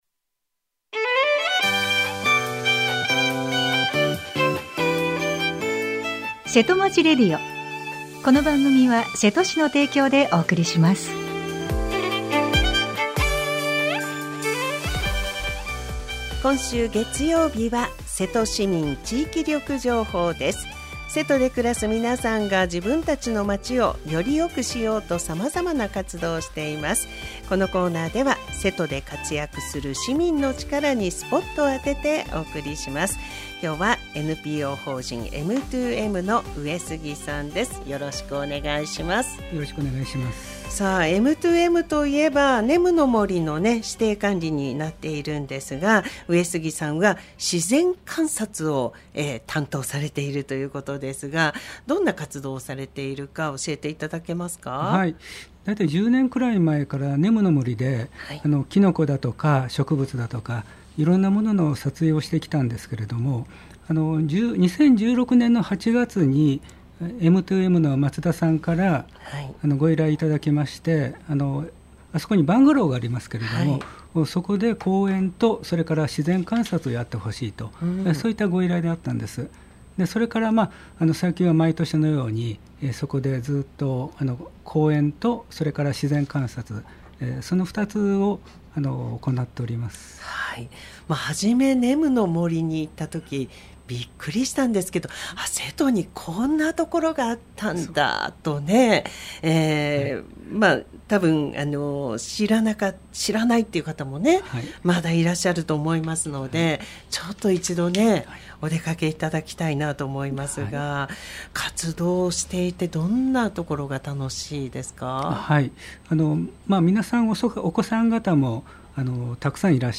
, 生放送 | radiosanq-hp | 2025年7月14日 9:30 AM | 2025年7月14日（月） は コメントを受け付けていません